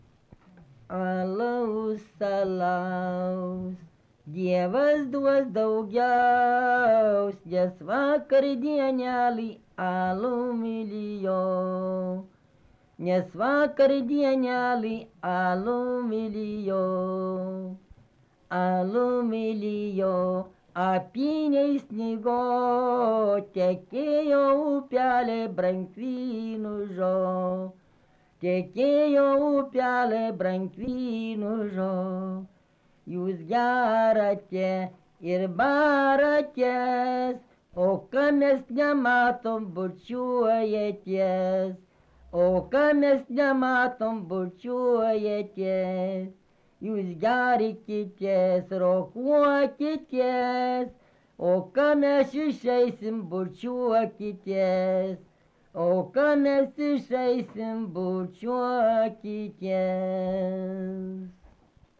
Mardasavas
vokalinis